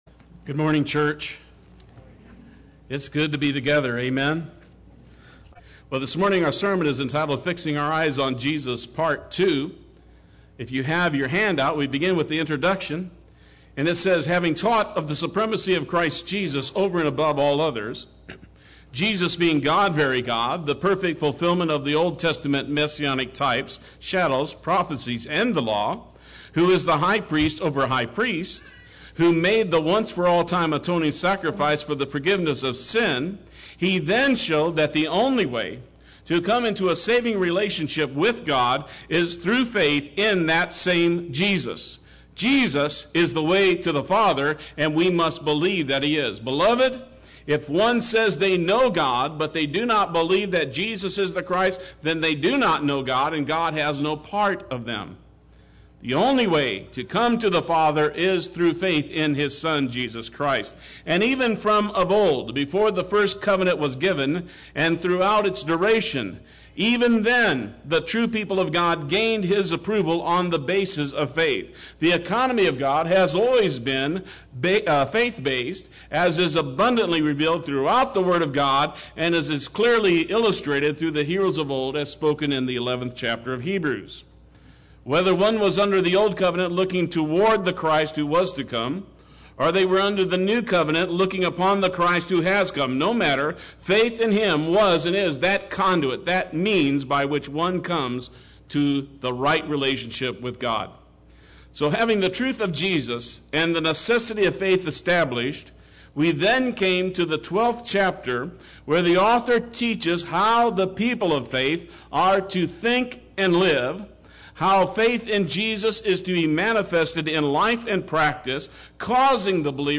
Play Sermon Get HCF Teaching Automatically.
Part 2 Sunday Worship